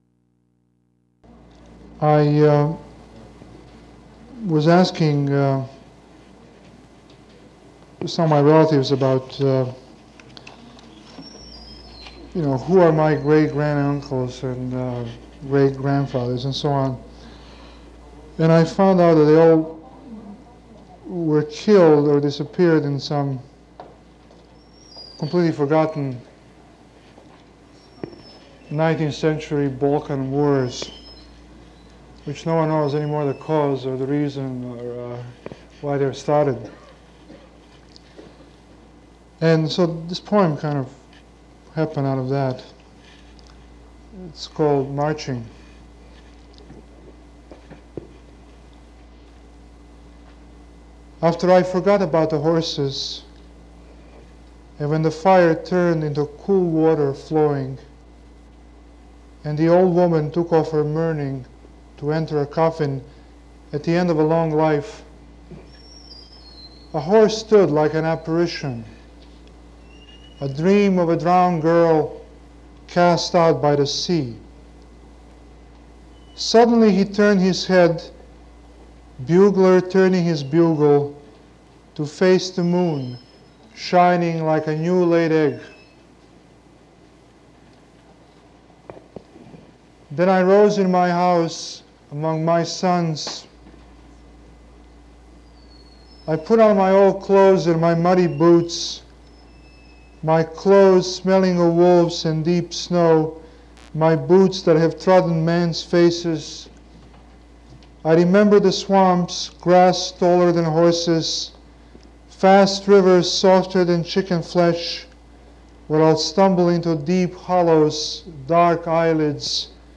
Charles Simic at Sir George Williams University, The Poetry Series, 19 November 1971 - SpokenWeb Search Engine
Production Context: Documentary recording